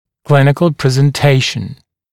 [‘klɪnɪkl ˌprezn’teɪʃn][‘клиникл ˌпрэзн’тэйшн]клиническая картина